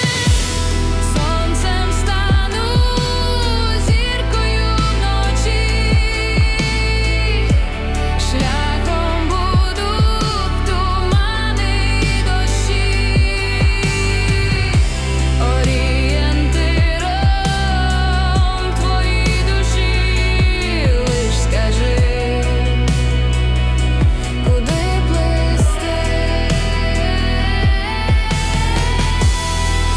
Жанр: Поп музыка / Русский поп / Русские
Pop, Vocal